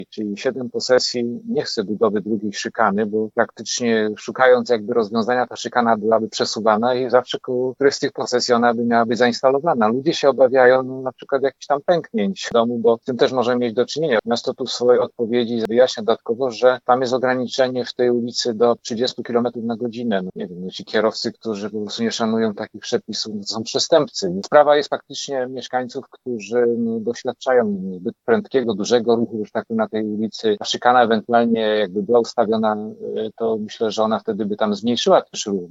To trudny spór do rozwiązania – mówi Marek Budniak Radny miasta Zielona Góra: